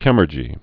(kĕmər-jē, kĭ-mûr-)